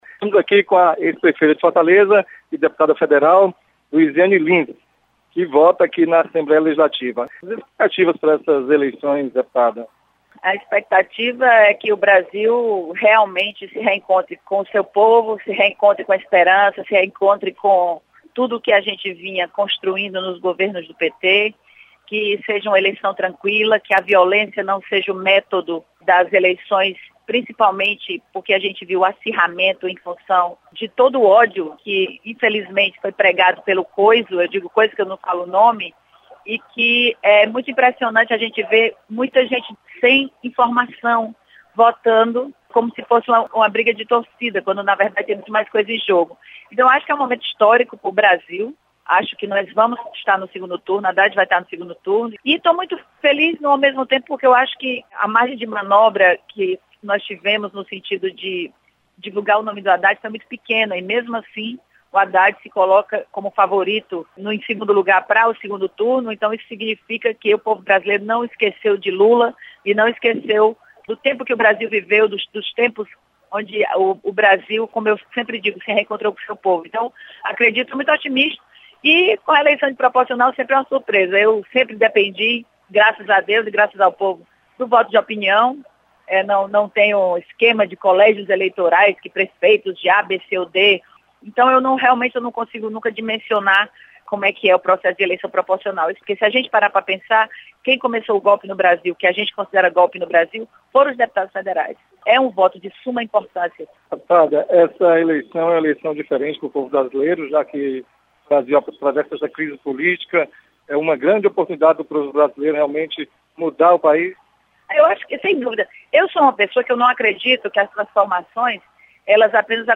Ex-prefeita Luizianne Lins vota na Assembleia Legislativa. Repórter